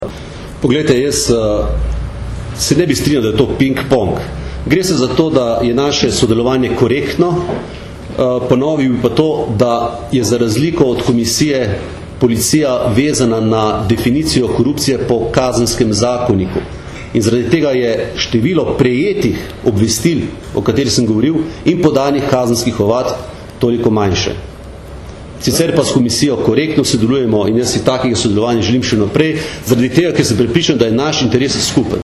Na novinarski konferenci je mag. Jevšek odgovoril tudi na aktualna vprašanja glede zadnjih odmevnejših zadev.